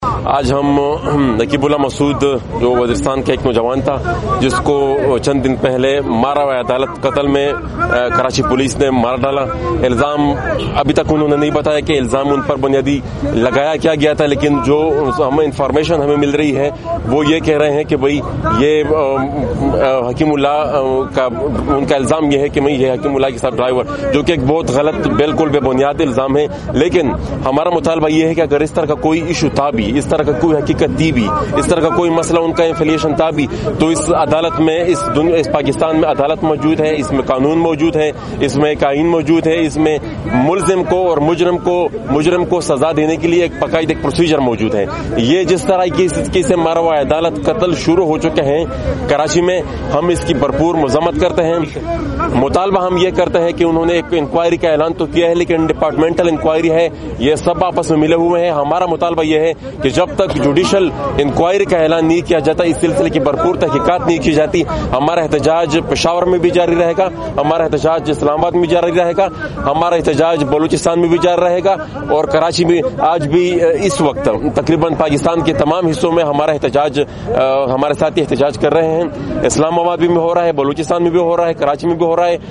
وائس آف امریکہ سے بات چیت